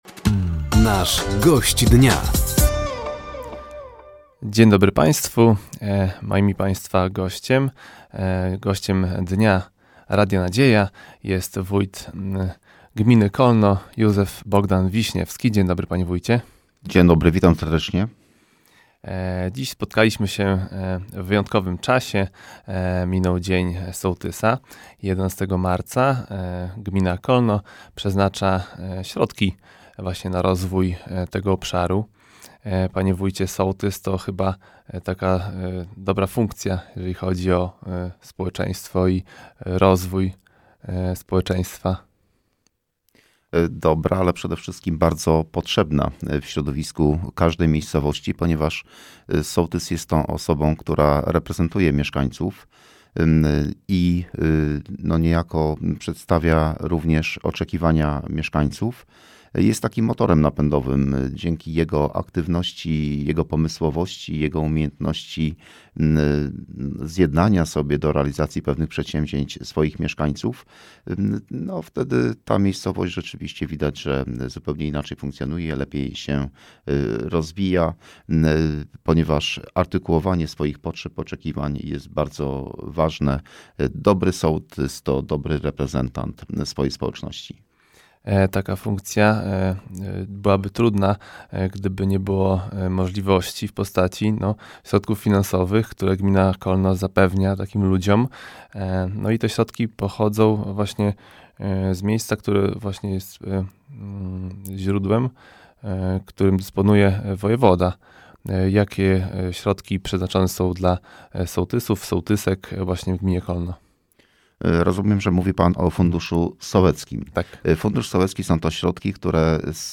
Gościem Dnia Radia Nadzieja był wójt gminy Kolno, Józef Wiśniewski. Tematem rozmowy był między innymi Fundusz Sołecki i budowa Centrum Opiekuńczo Mieszkalnego oraz plany gminy na przyszłość